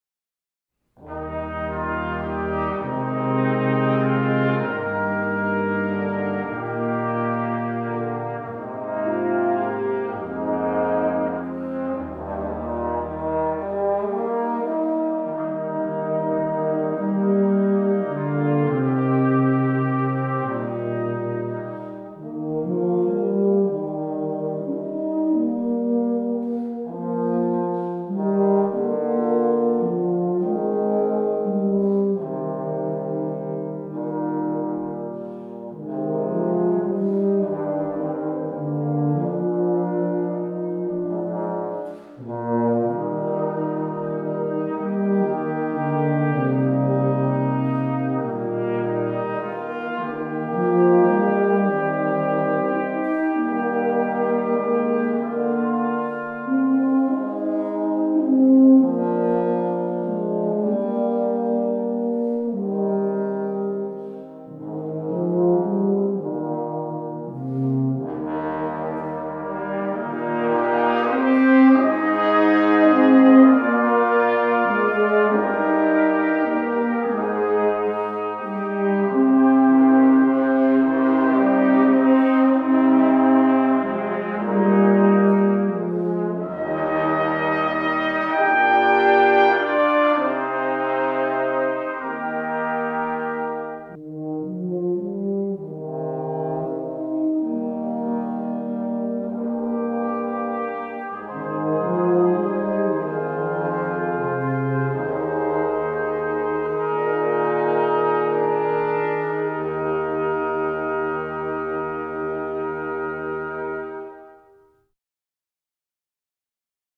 For Brass Quintet
Tuba feature.